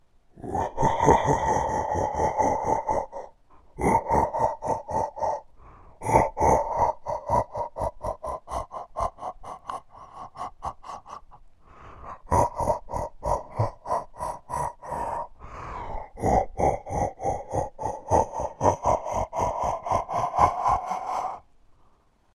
恐怖片 " 邪恶的笑声
描述：我录制的一些恐怖的声音。
Tag: 吓人的笑 鬼笑 邪恶的笑 干扰 困扰 可怕的 邪恶 坏人 令人毛骨悚然 恐怖的笑 恐怖 小人 邪恶笑 吓人